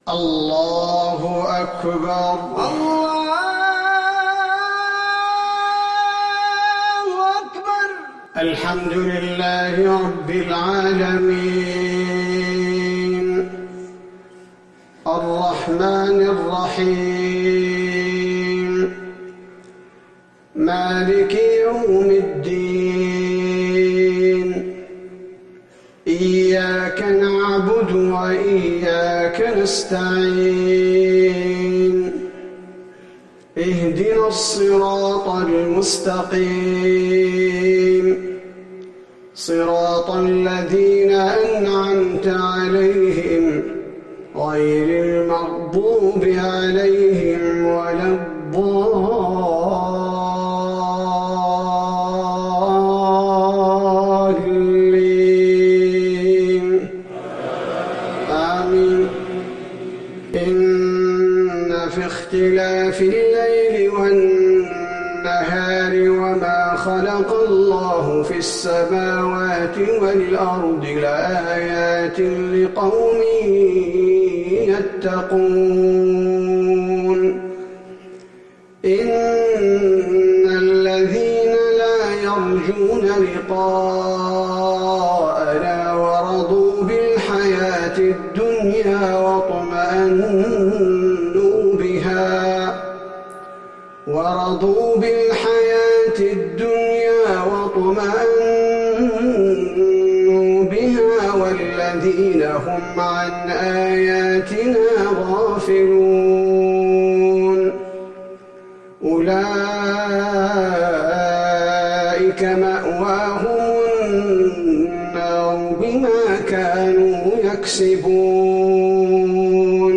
صلاة المغرب للقارئ عبدالباري الثبيتي 5 جمادي الآخر 1441 هـ
تِلَاوَات الْحَرَمَيْن .